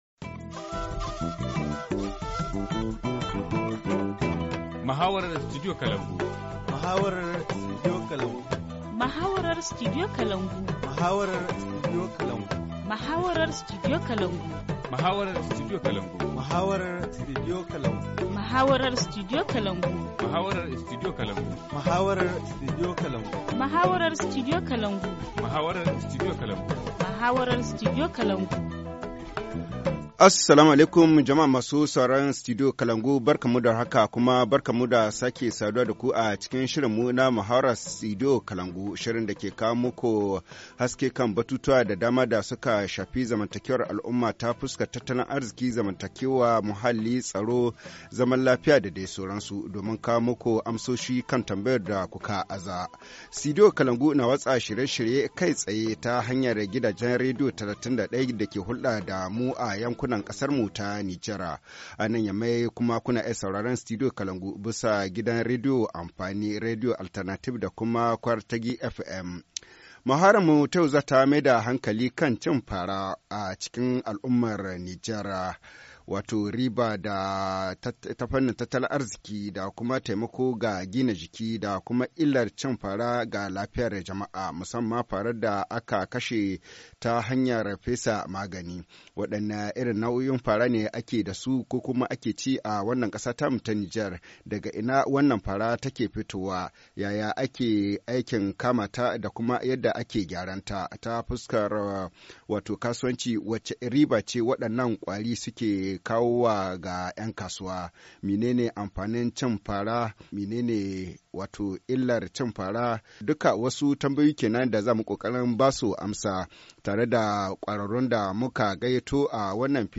Forum Hausa 09/12/2017 : La consommation des criquets dans la société nigérienne, retombées économiques, apports nutritifs et conséquences sur la santé par l’usage des pesticides - Studio Kalangou - Au rythme du Niger